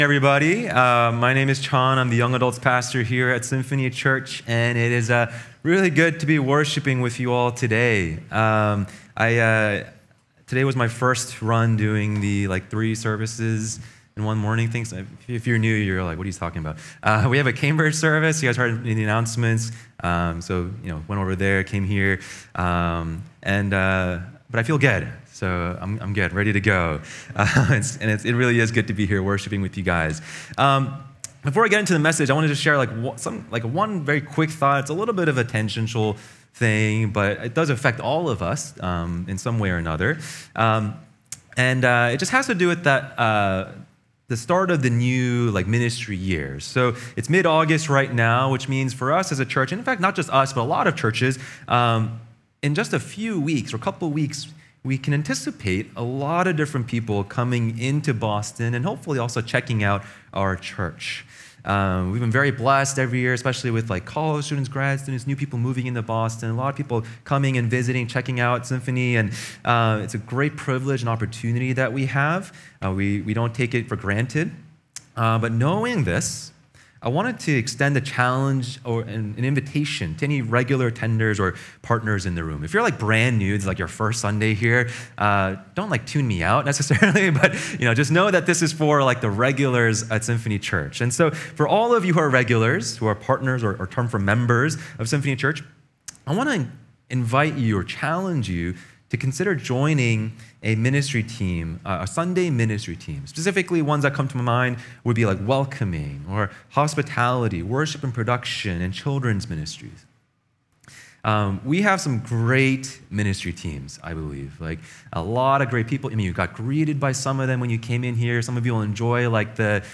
Sermons | Symphony Church
Current Sermon